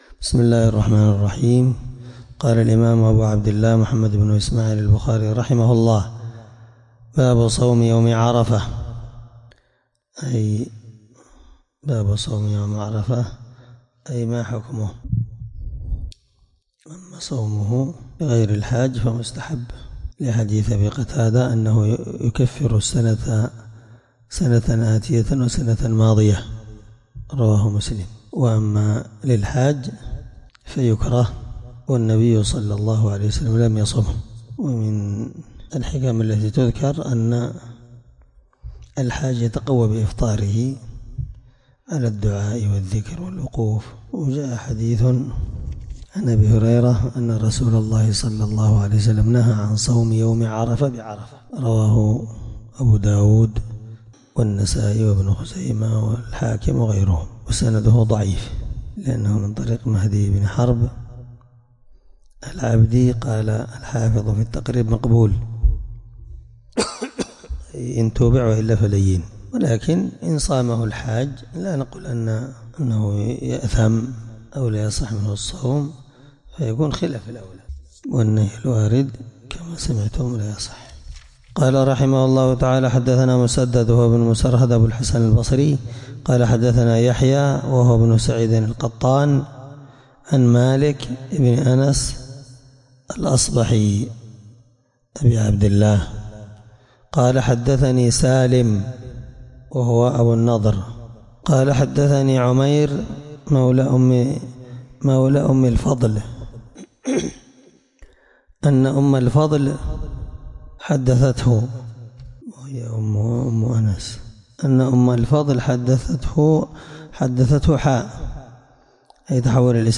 الدرس57 من شرح كتاب الصوم رقم(1988-1989)من صحيح البخاري